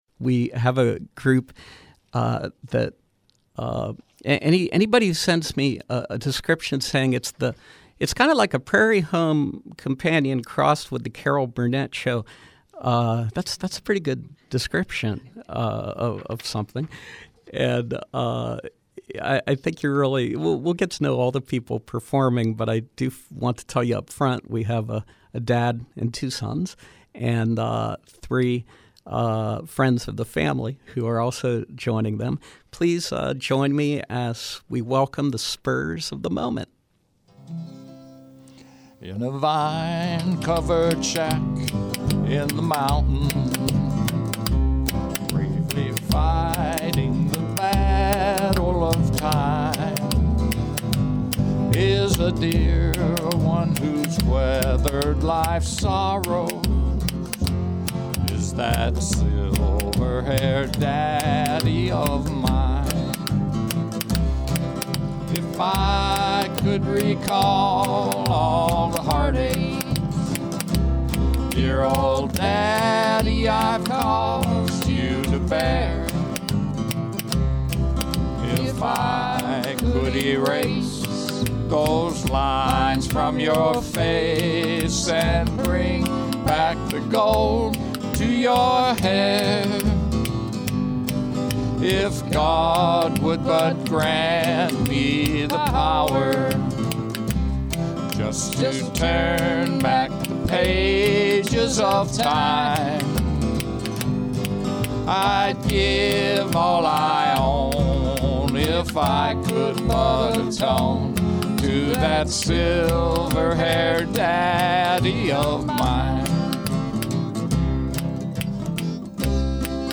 Country-western chestnuts, popular standards and humor